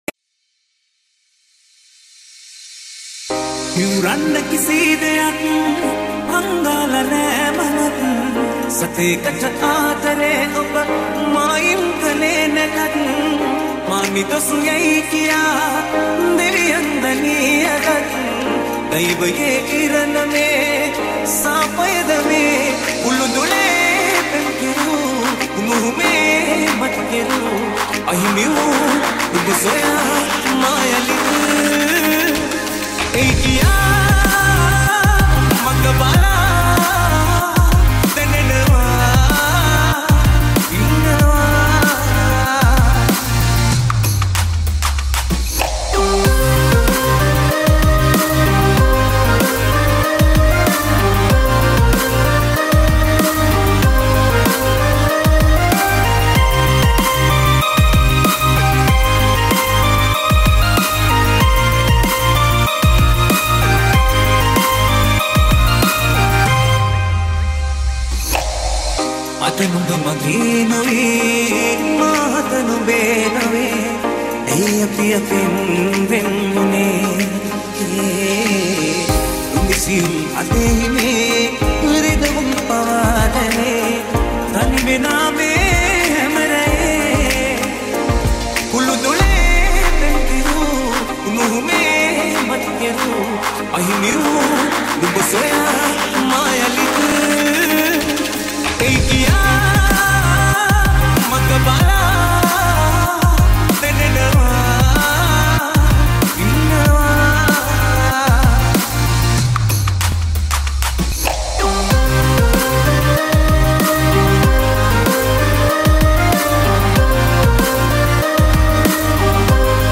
High quality Sri Lankan remix MP3 (5).